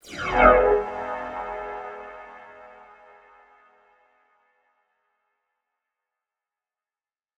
Chords_Emaj_01.wav